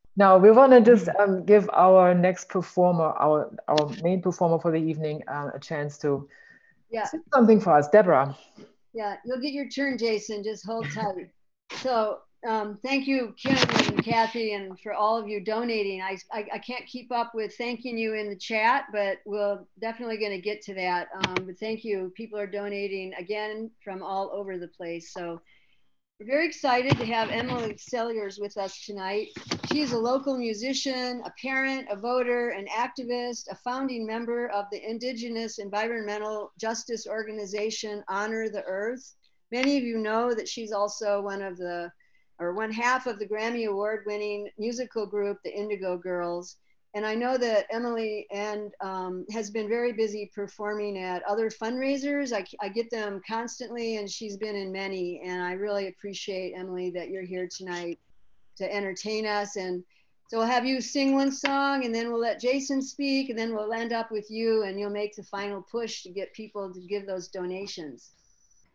(audio captured from zoon meeting)
02. emily saliers introduction (1:08)